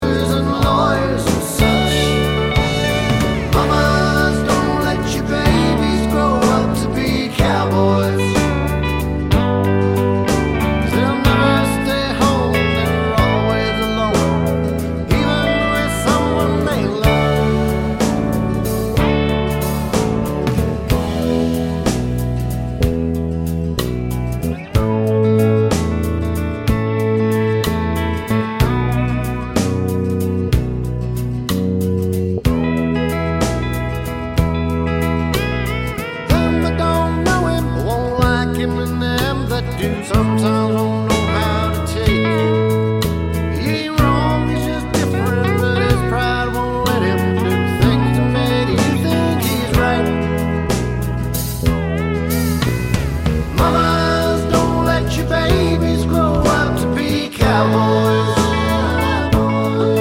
For Solo Singer Country (Male) 2:55 Buy £1.50